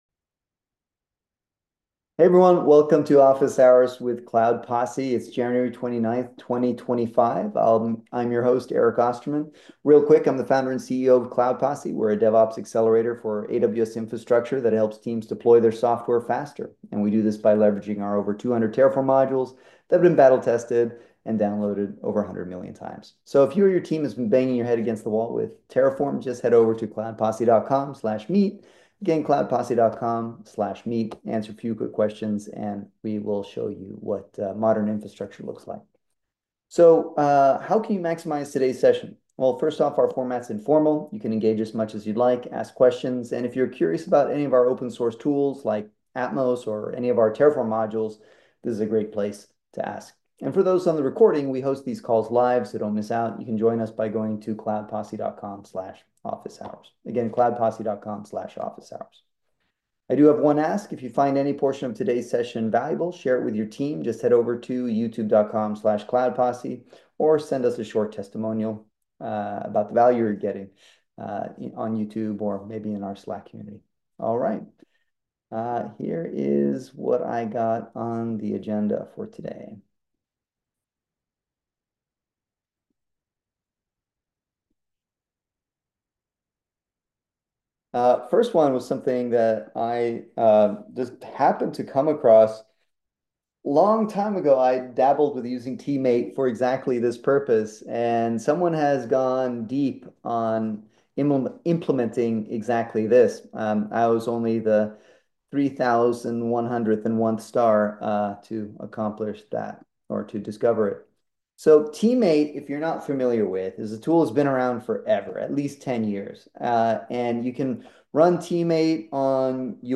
cultivation experts